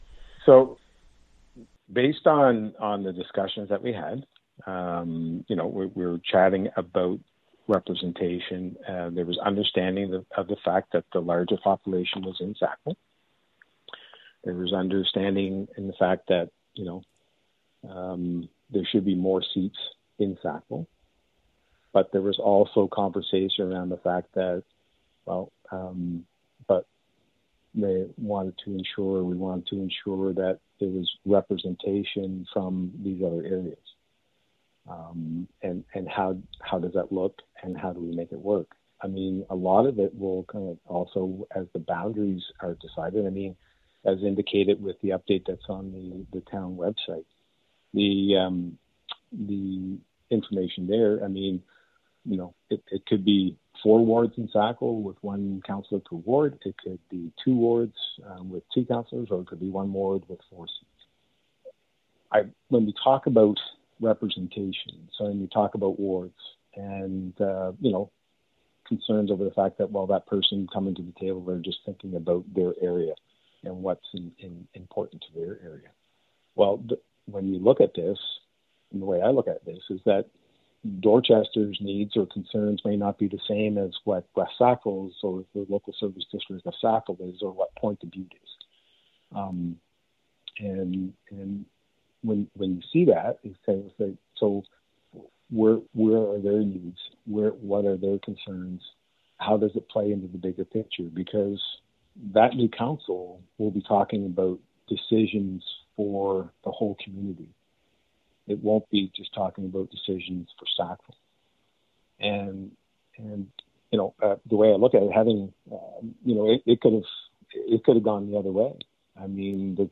She asked him about the decision to set aside representation by population in favour of more representation for rural areas in the new Entity 40. Here’s part of their conversation.